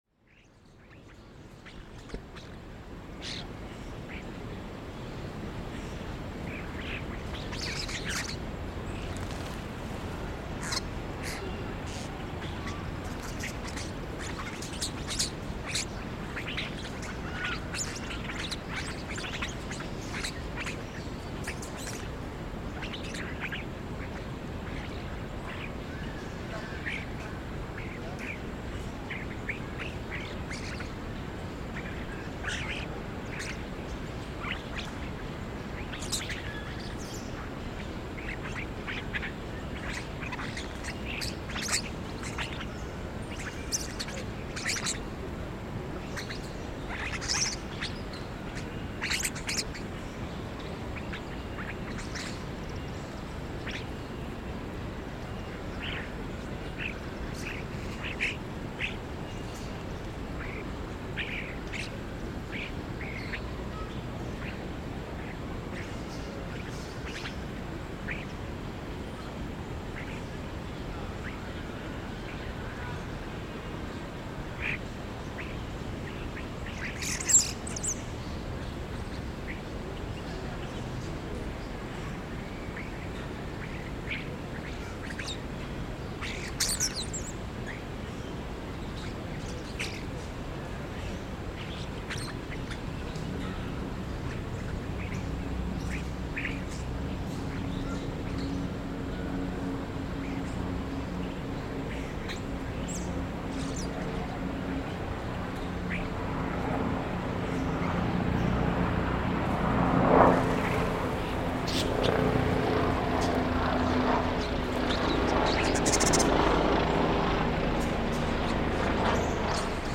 Starlings and helicopters in Central Park
Starlings fight and feed in Central Park, New York, as helicopters pass overhead.